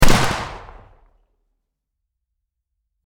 Gunshot
Gunshot.mp3